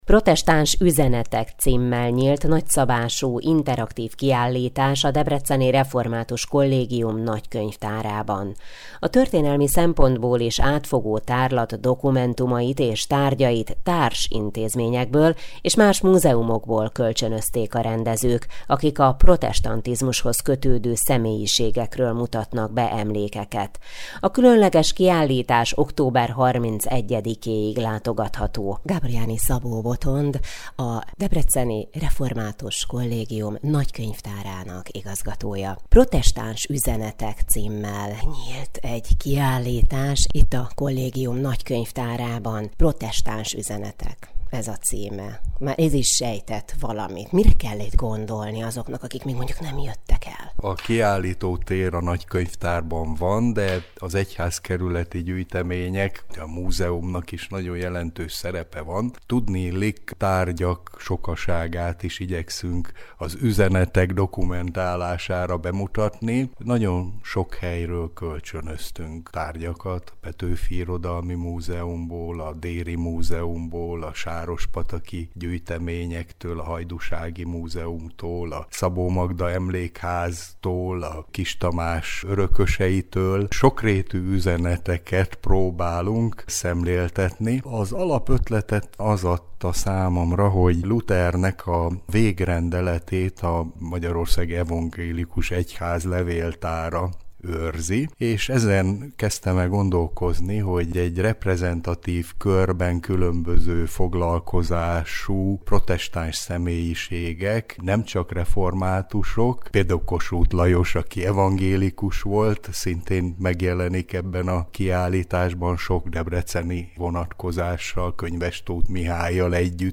összeállítást az Európa Rádióban